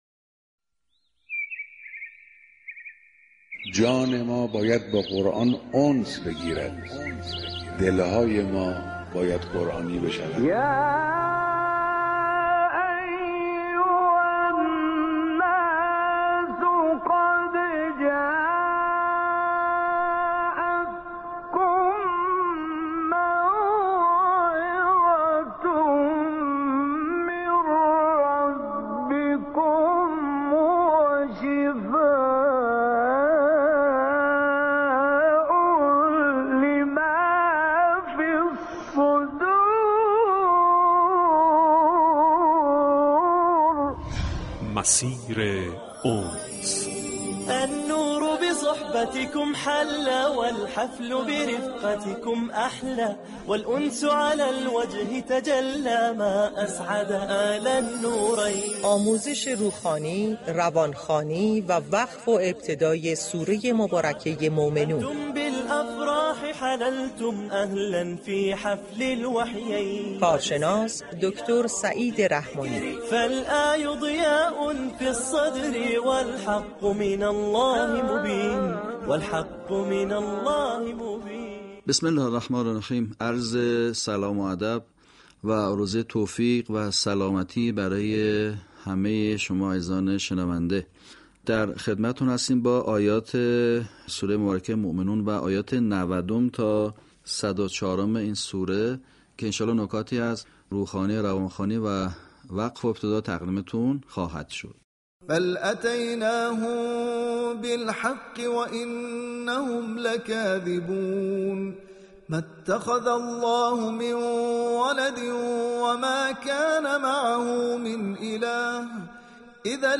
آموزش روخوانی و روانخوانی آیات 90 تا 104 سوره مباركه مومنون